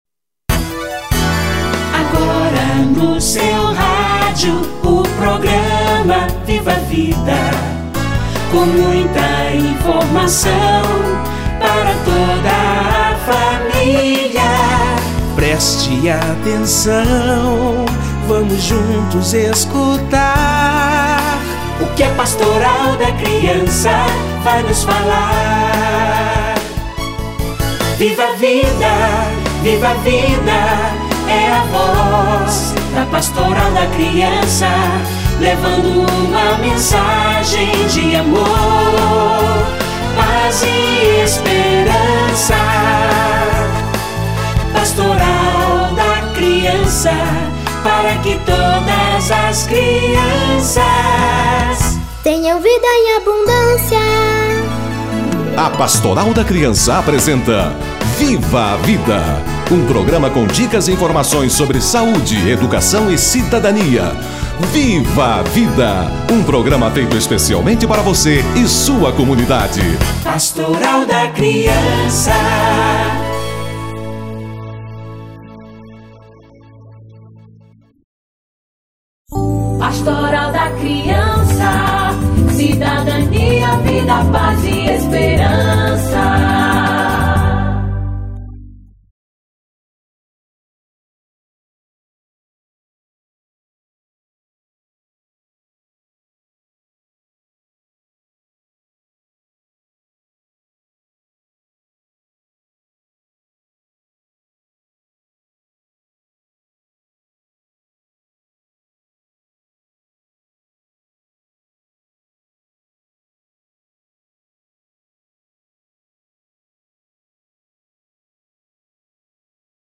Dia da Consciência Negra - Entrevista